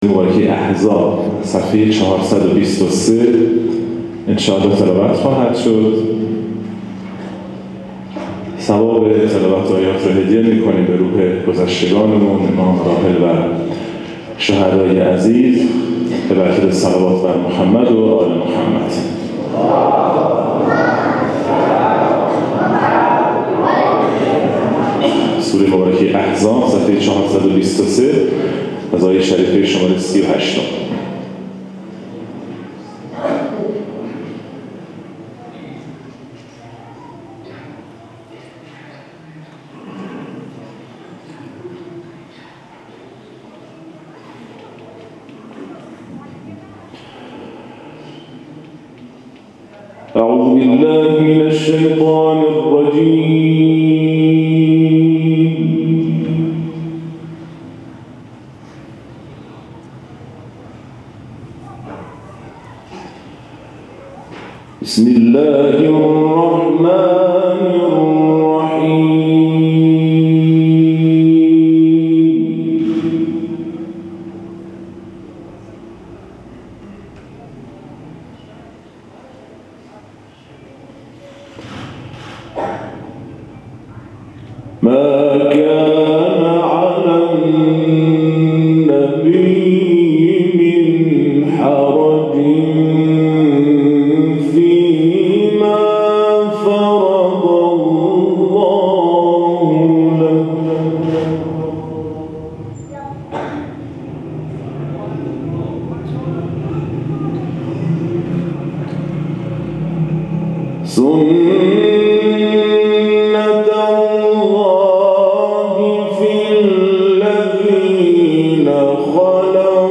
گروه جلسات و محافل ــ محفل انس با قرآن شهر قصرقند روز گذشته، هجدهم بهمن‌ماه برگزار شد و قاریان بین‌المللی کشورمان به تلاوت آیاتی از کلام الله مجید پرداختند.